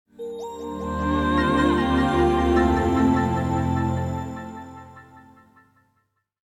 Startup.mp3